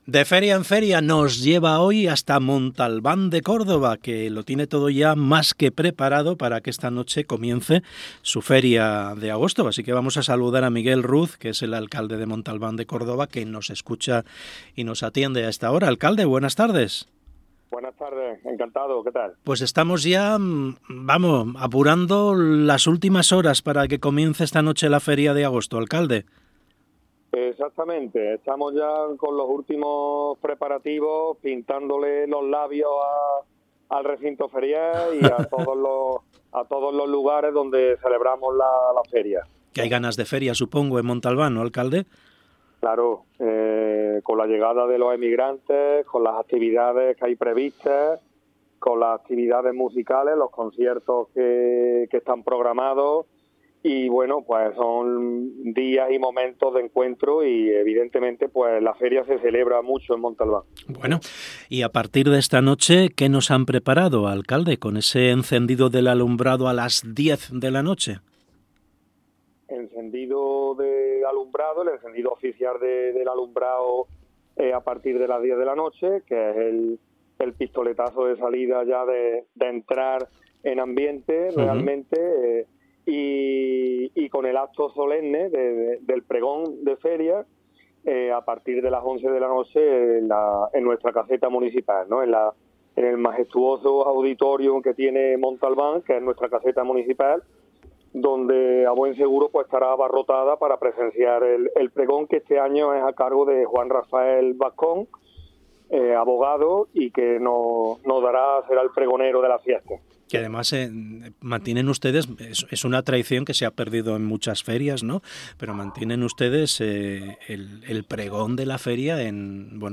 Entrevista Miguel Ruz. Feria de Montalbán 2025
Miguel Ruz, alcalde de Montalbán, ha pasado por el programa Hoy por Hoy de Verano.